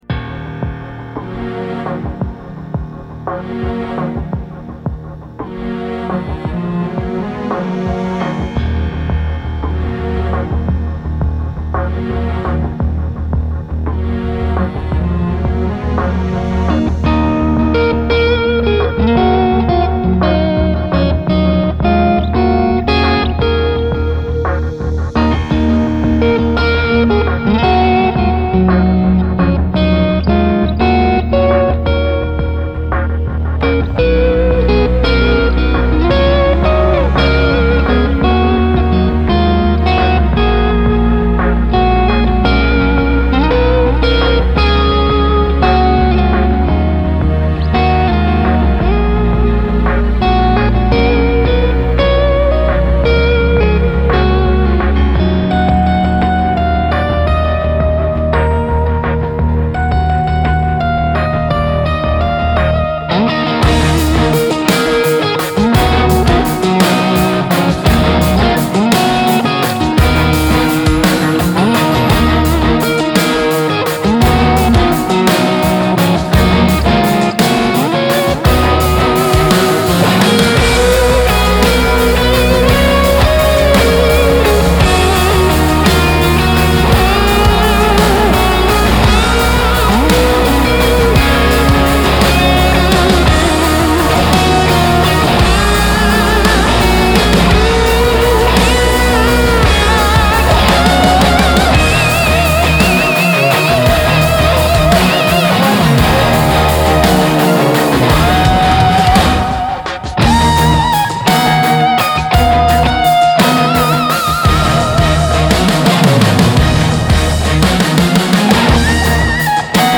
speed riffs